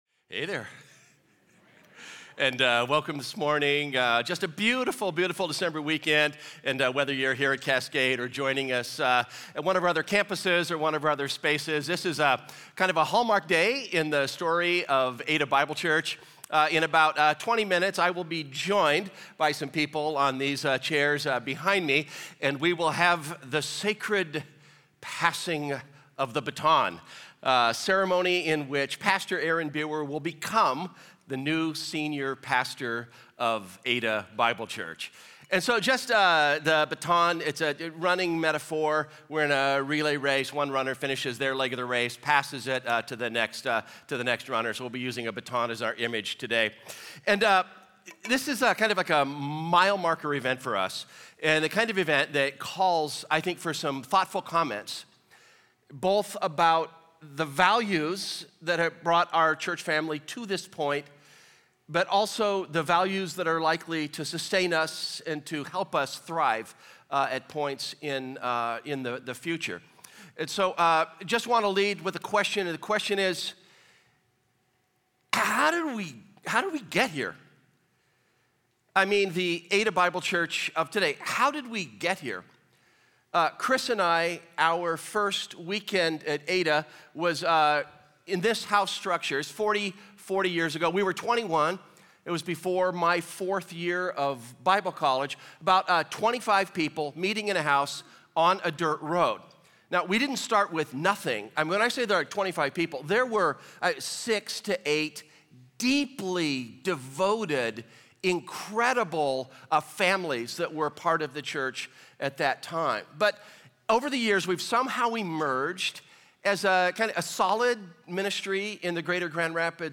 Series: Transition Service How Did We Get Here?